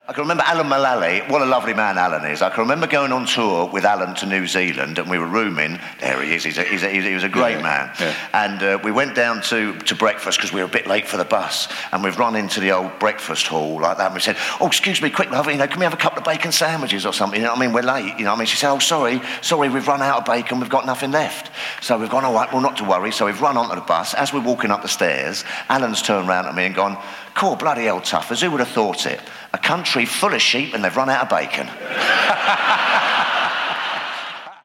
Here are some snippets from our live and recorded work.